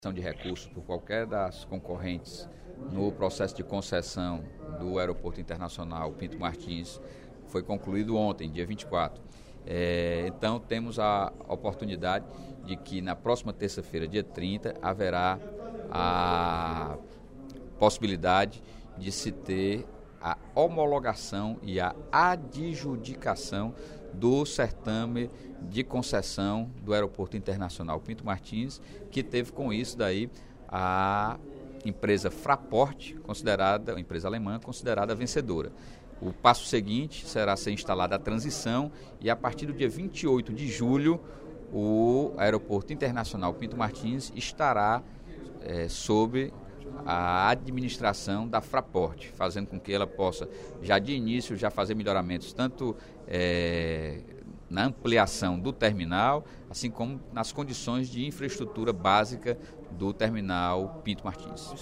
O deputado Sérgio Aguiar (PDT) anunciou, durante o primeiro expediente da sessão plenária desta quarta-feira (24/05), que vai ser realizada a homologação do certame de concessão do Aeroporto Internacional Pinto Martins à empresa alemã Fraport AG Frankfurt Airport Services na próxima terça-feira (30/05).